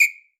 9SHORTWHI.wav